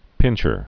(pĭnchər)